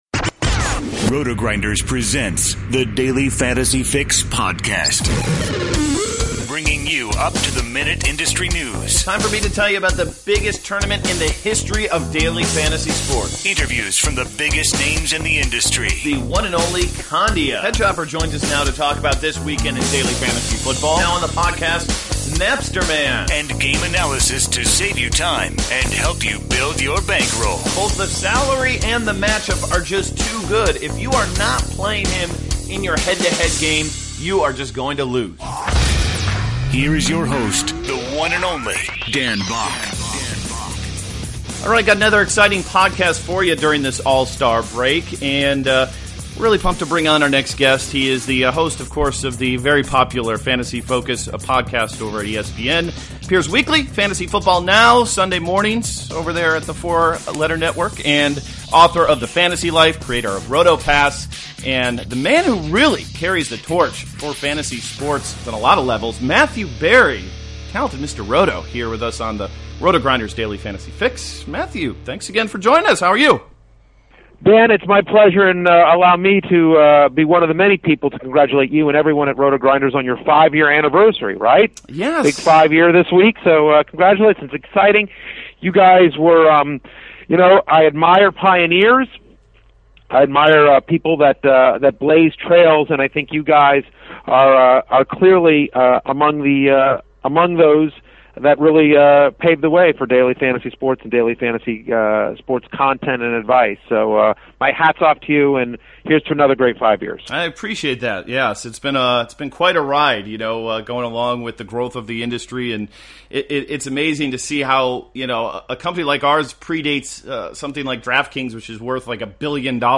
RG Daily Fantasy Fix: Matthew Berry Interview